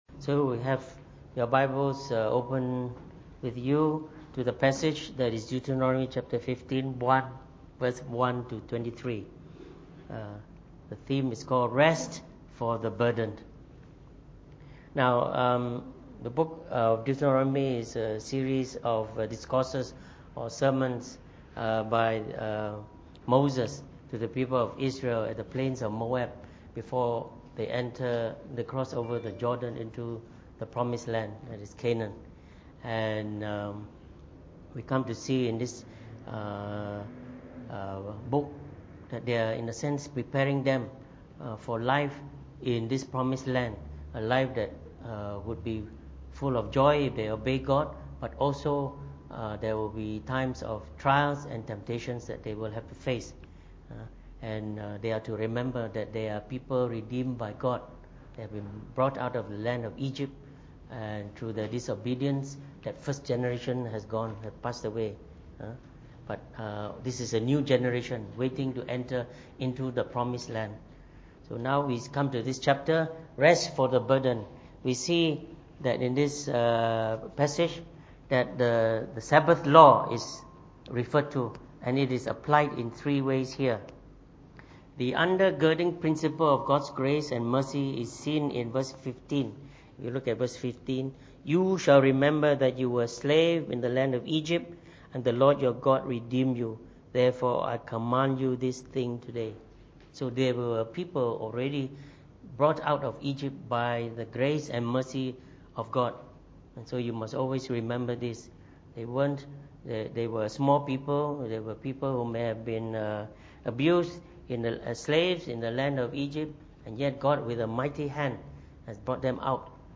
Preached on the 16th of May 2018 during the Bible Study, from our series on the book of Deuteronomy.